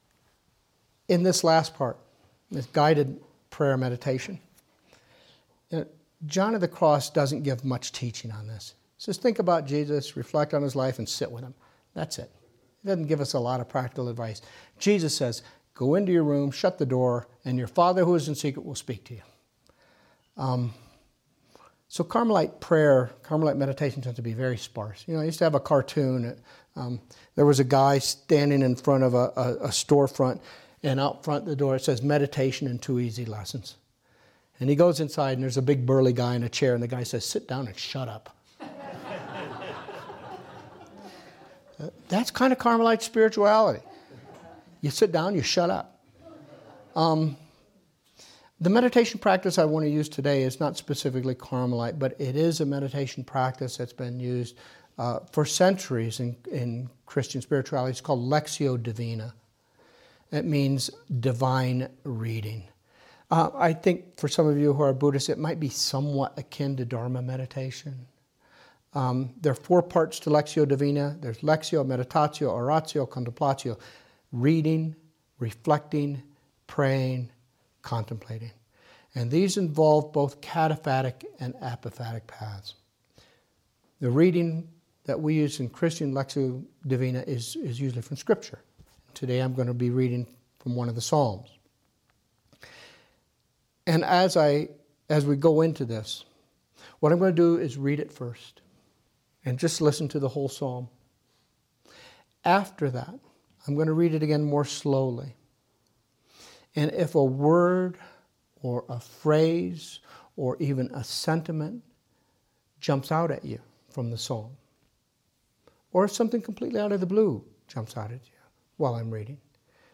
Lectio Divina